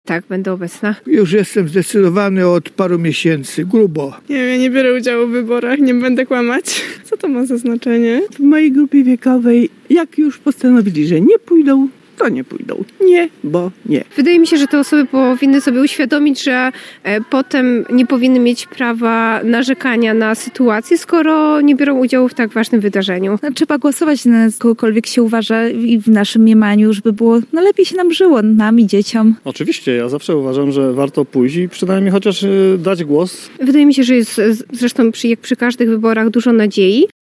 Zapytaliśmy przechodniów w Rzeszowie, czy pójdą do urn wyborczych, by oddać głos na swojego kandydata?